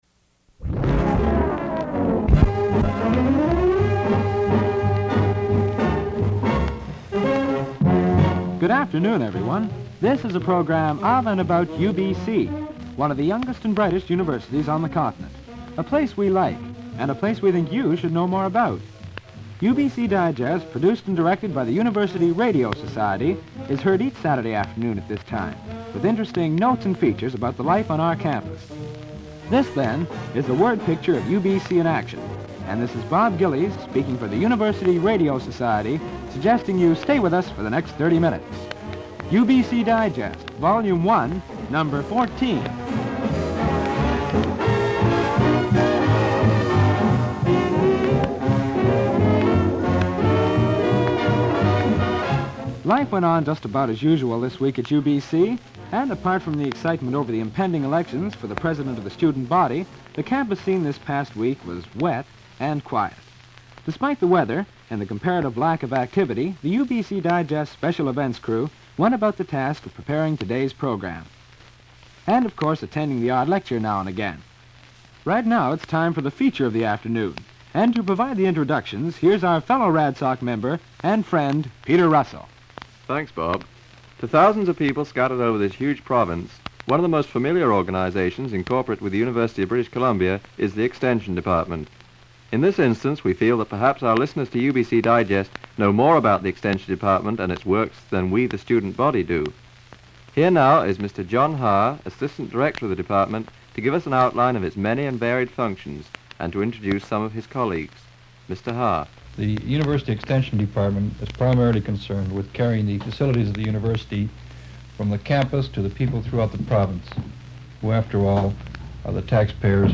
Recording of an episode of the UBC Radio Society's UBC Digest program.